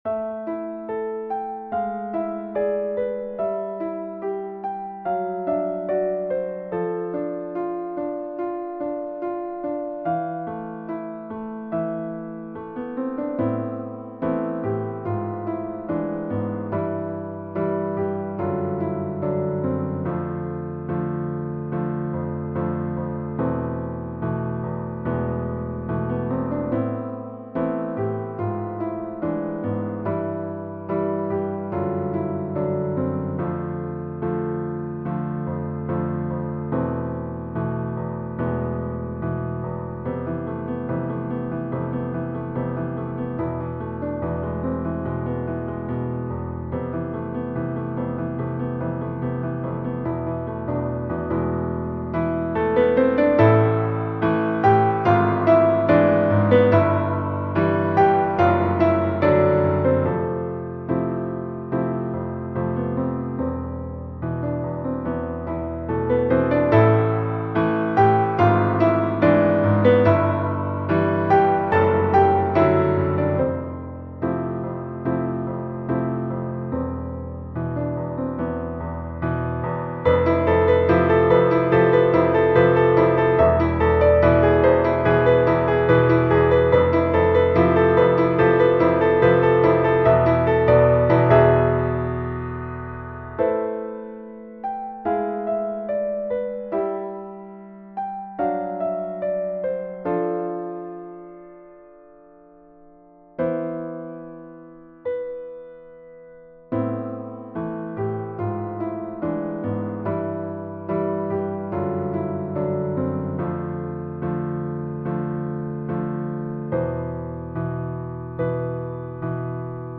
Ноты для фортепиано.
*.mid - МИДИ-файл для прослушивания нот.